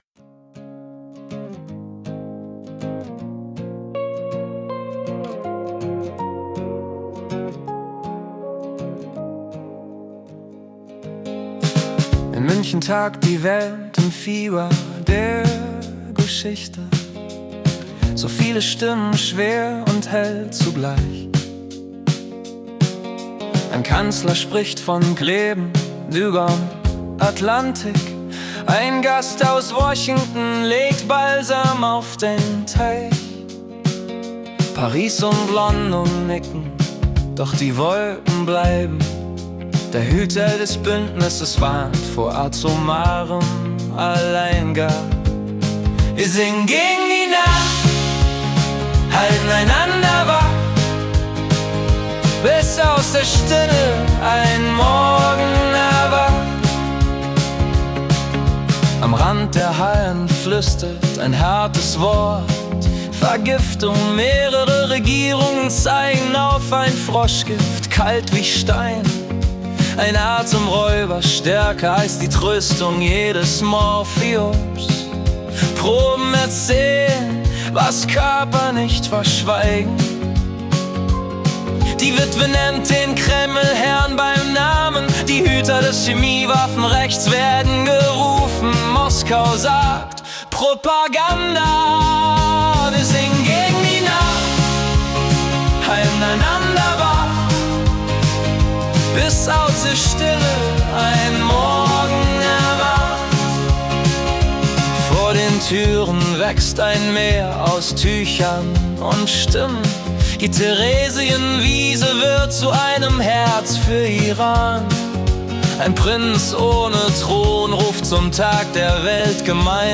Die Nachrichten vom 16. Februar 2026 als Singer-Songwriter-Song interpretiert.